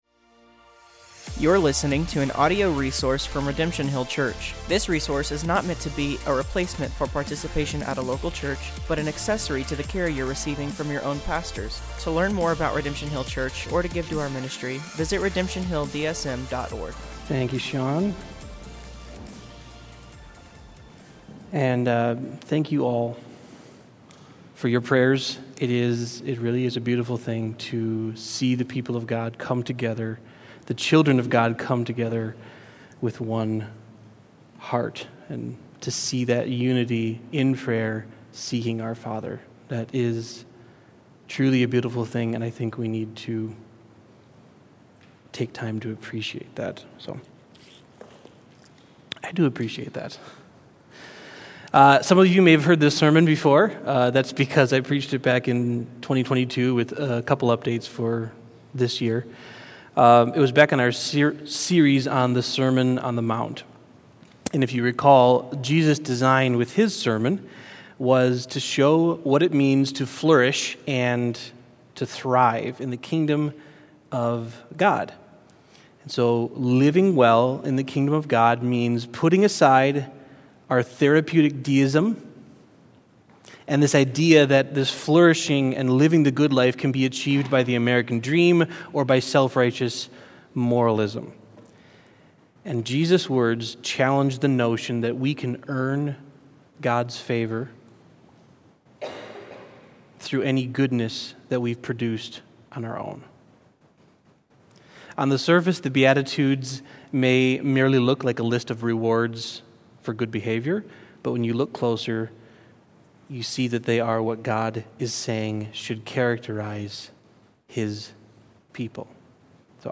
Sermons | Redemption Hill Church Des Moines, IA